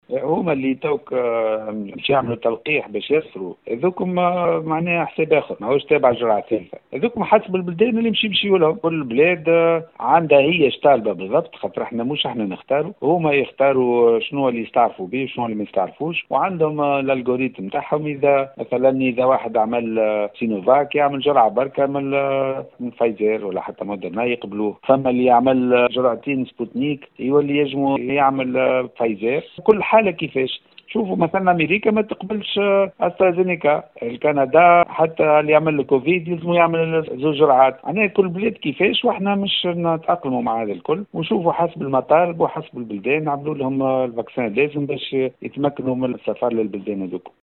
أوضح المدير العام للمركز العام الوطني لليقظة الدوائية ورئيس اللجنة العلمية للتلقيح رياض دغفوس، في تصريح ل “ام اف ما” أنه سيتم تطعيم المسافرين إلى الخارج وفق ما تشترطه كل دولة لتمكينهم من السفر.